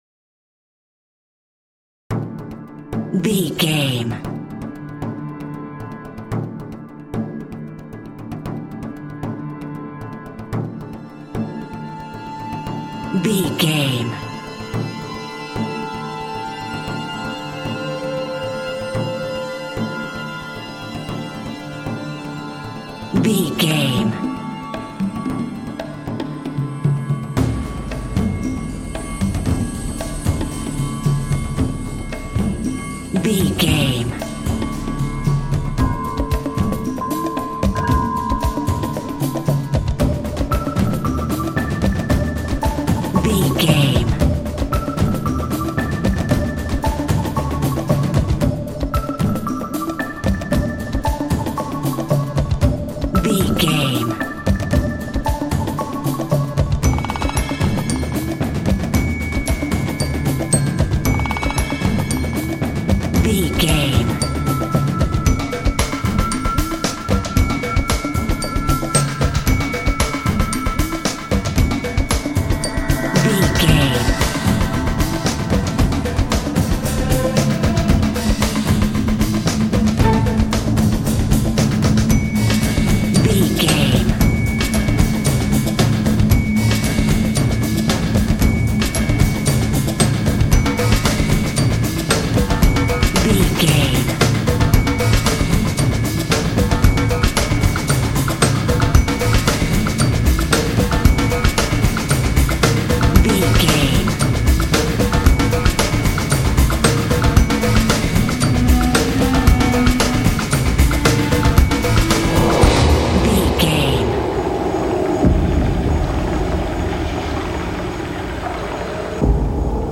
Aeolian/Minor
C#
tension
foreboding
synthesiser
strings
drums
contemporary underscore